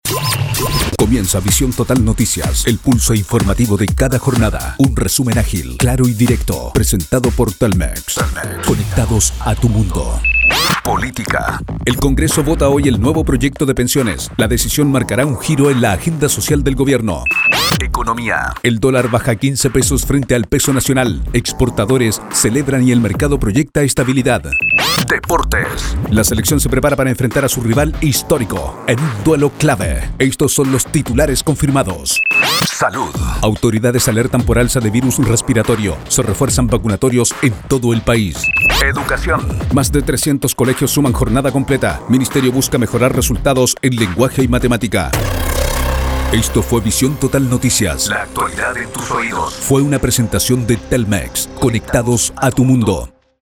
Male
Adult (30-50)
Radio / TV Imaging
LocucióN Noticias